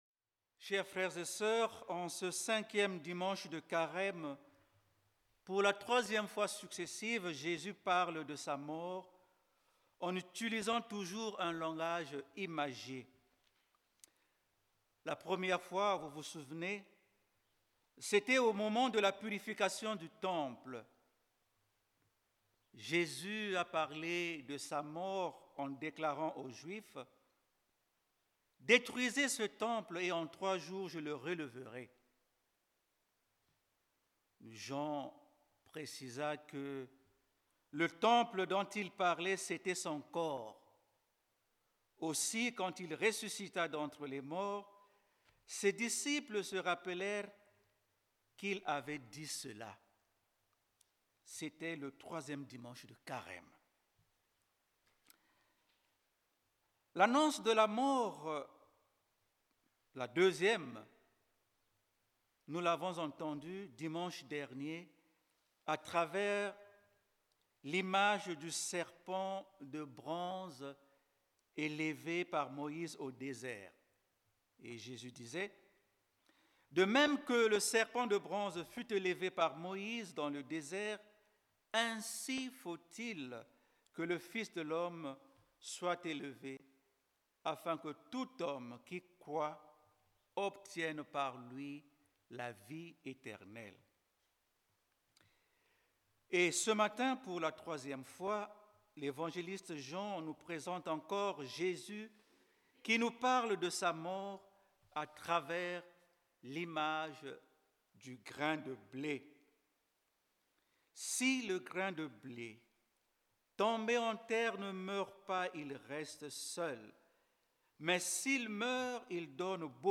Grâce à un enregistrement en direct , ceux qui le souhaitent peuvent écouter sa réflexion.